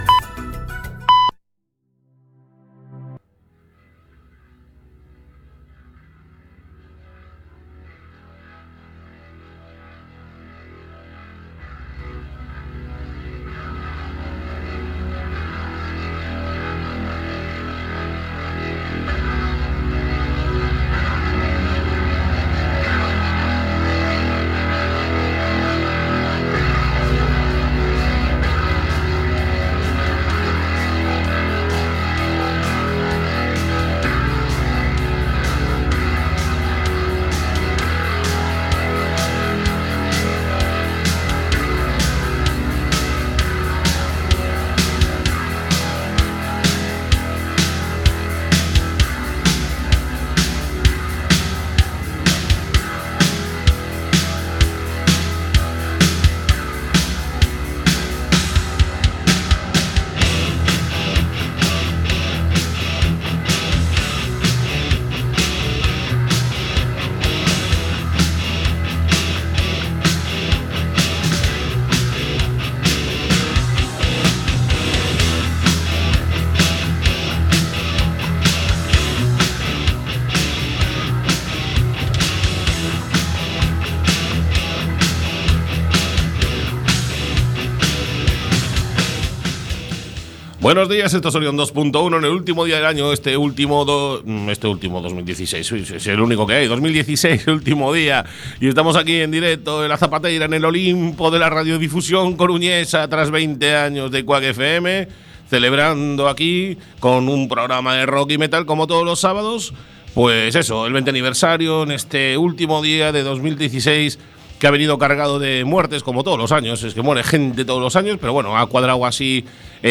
Programa de Rock y heavy en todas sus vertientes con un amplio apartado de agenda, de conciertos y eventos, en la ciudad y Galicia. Entrevistas, principalmente a bandas gallegas, y repleto de novedades discográficas.